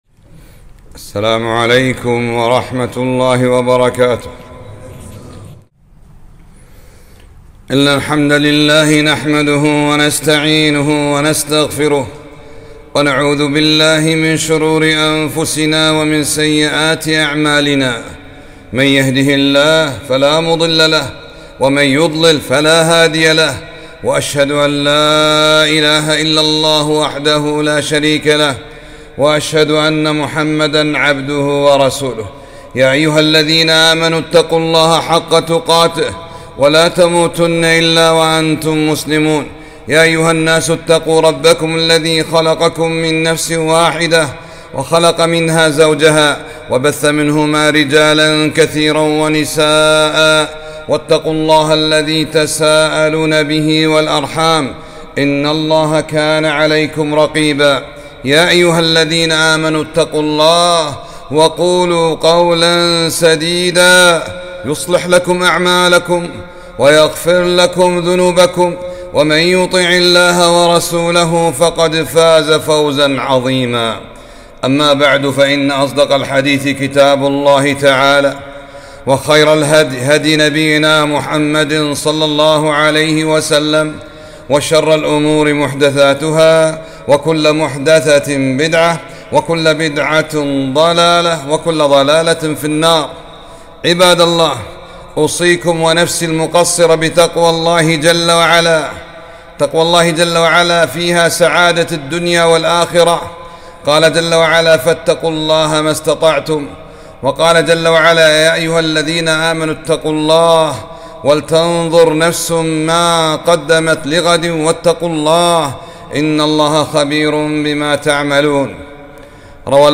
خطبة - وكان عند الله وجيها وليس عند الناس